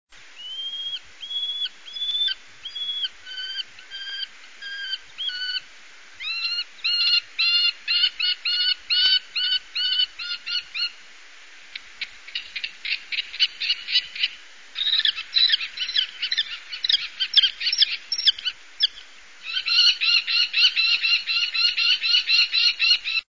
Canto del cernícalo vulgar
canto-cernicalo-vulgar.mp3